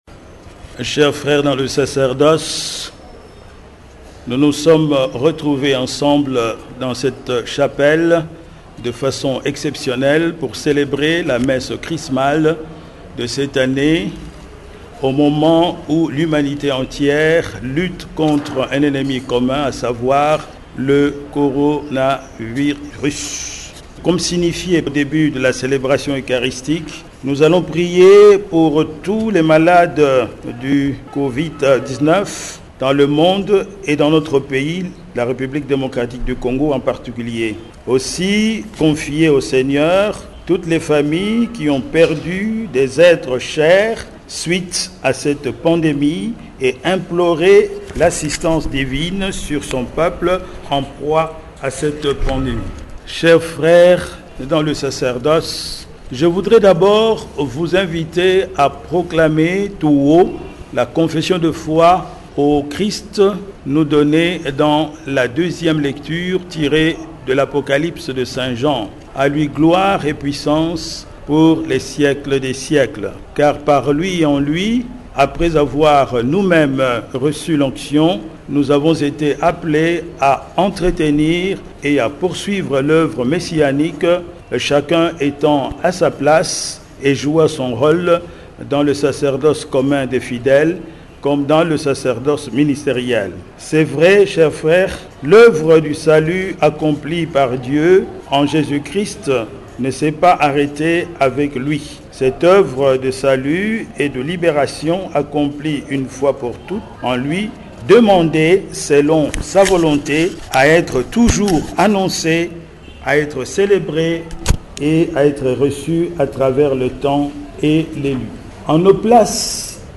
Homélie de l évêque messe chrismale 2020
homelie-de-l-eveque-messe-chrismale-2020.mpeg.mp3